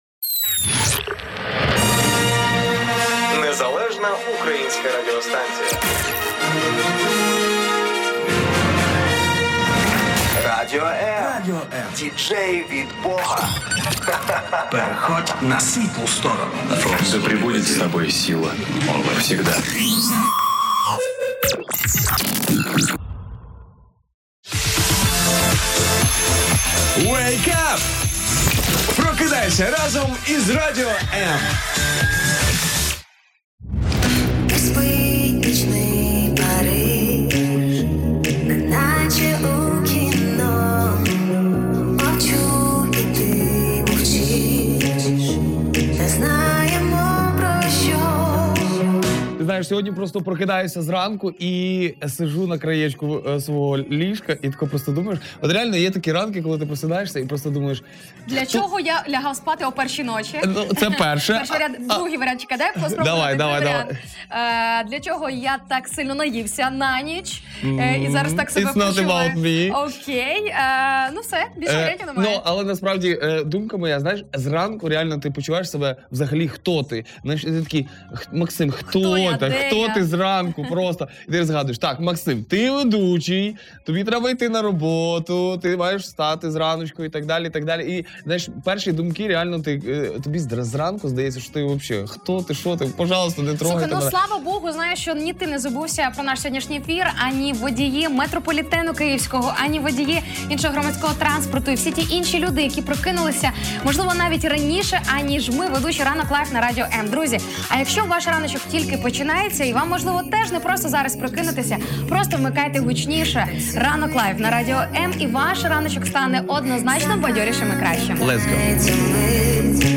говорили по-чоловічому Чи конфліктна ви людина? 26/01/2022 Як уникнути конфліктів і головне, як зрозуміти що ти - конфліктна людина? Гаряча тема нашого ранку.